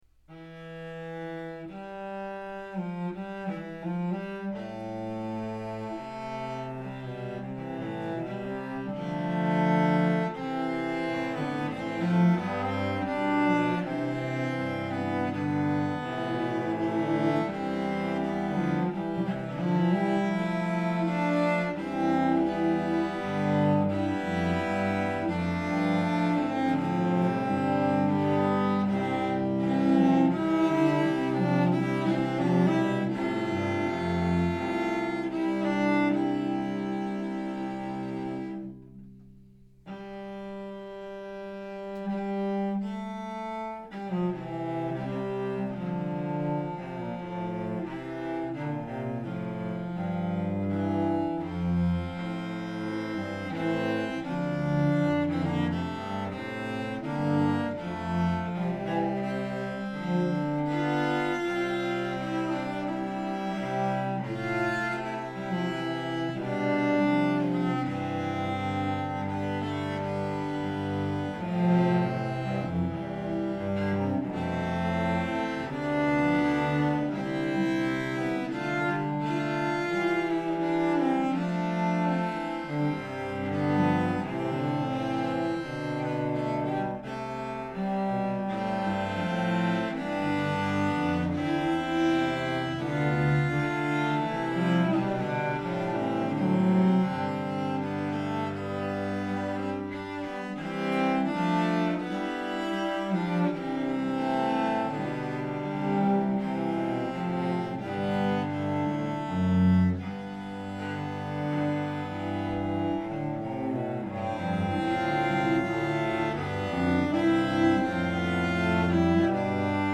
Cello Choir